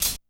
Medicated Hat 5.wav